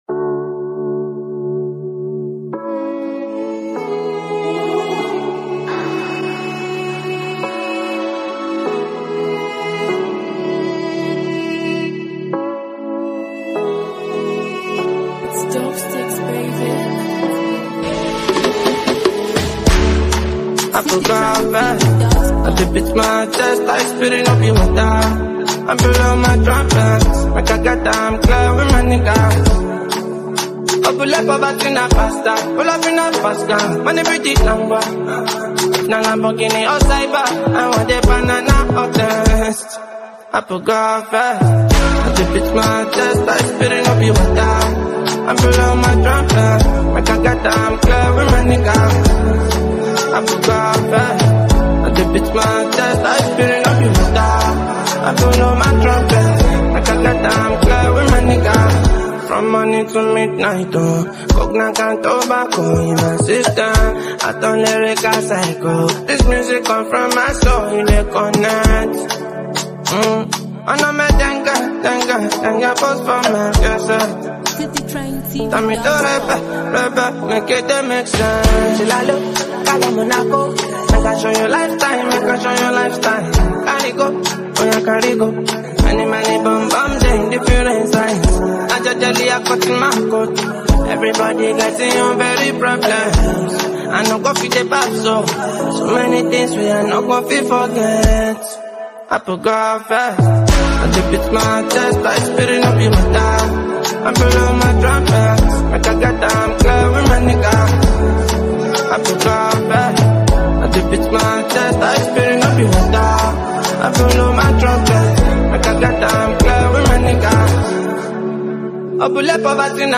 Renowned Nigerian Afrobeats talent and performer
an inspiring vibe
The music scene is excited to embrace this energetic release